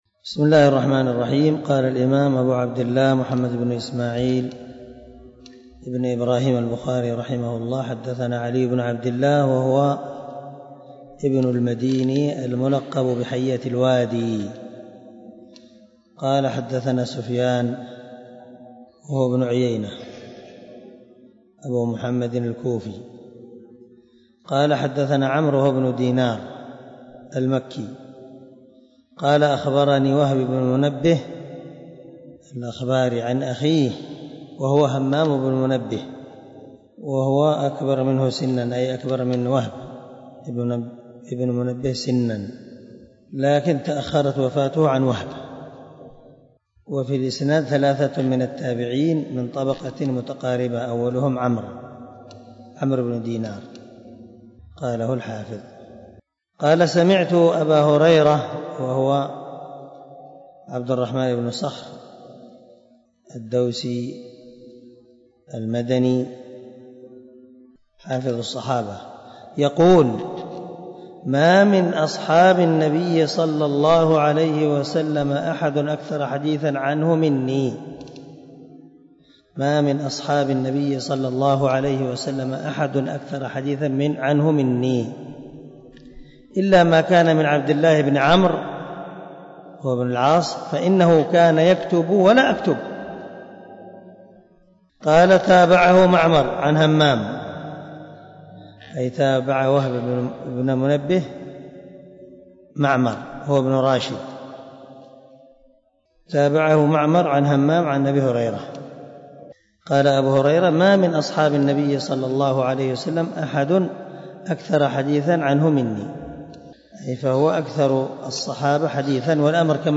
106الدرس 51 من شرح كتاب العلم حديث رقم ( 113 ) من صحيح البخاري